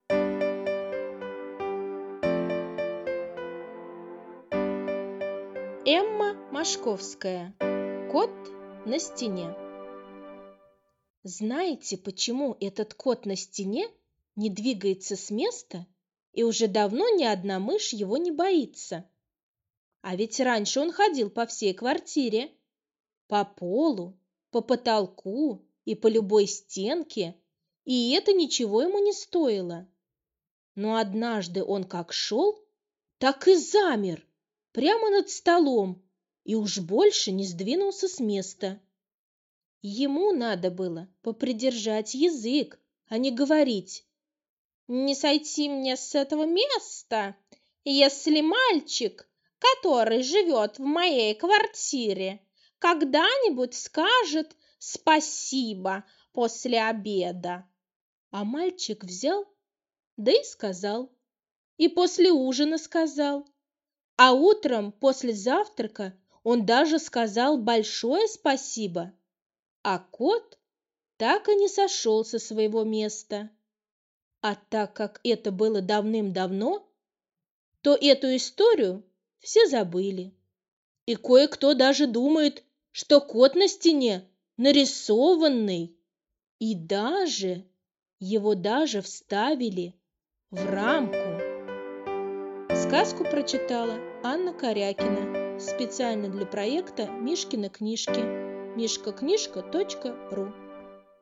Кот На Стене - аудиосказка Мошковской Э.Э. История про Кота, который пообещал не сходить с места, если мальчик скажет спасибо.